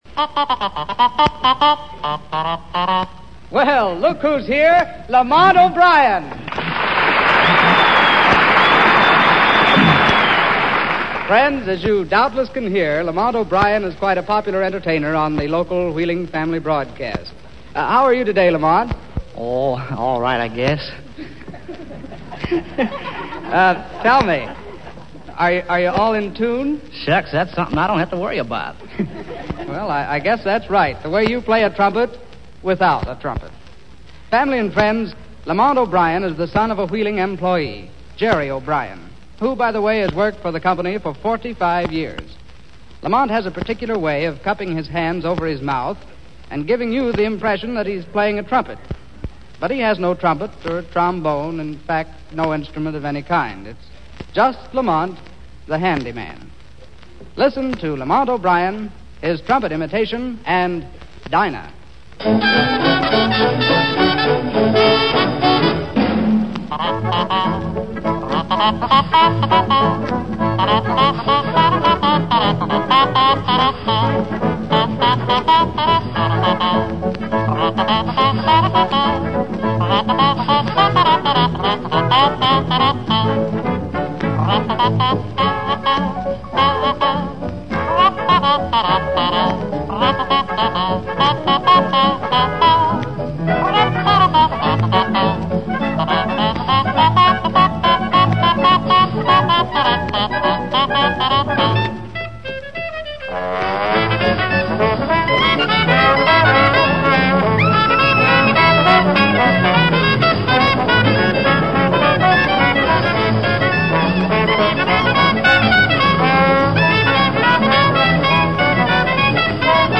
Sample sound files from the radio program: